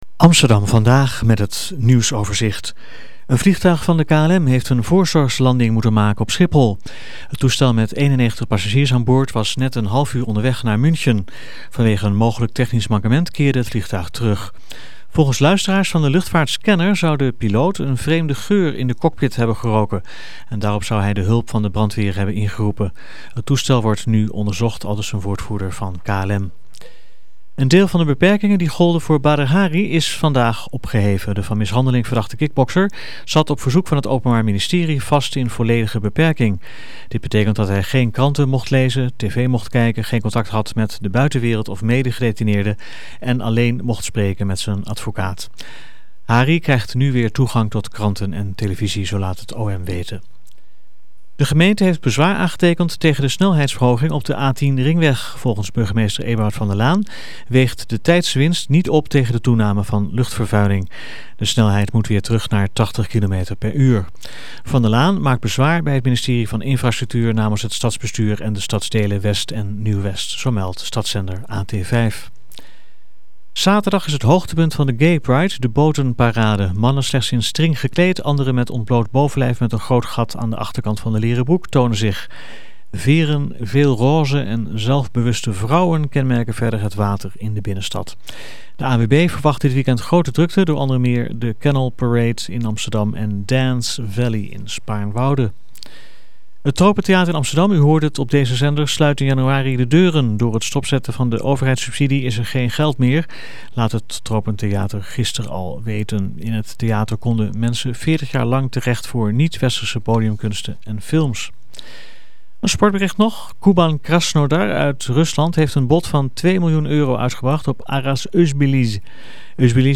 Nieuwsoverzicht